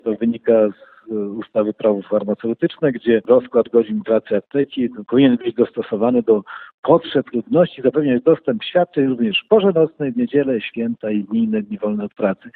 Mówi starosta powiatu ełckiego Marek Chojnowski.